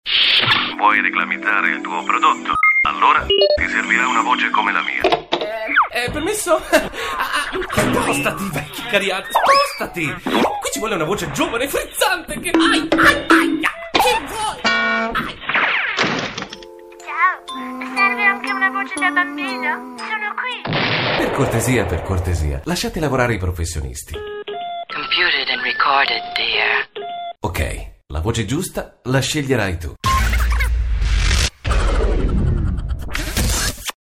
italienischer Sprecher.
Sprechprobe: Industrie (Muttersprache):
voice over artist italien.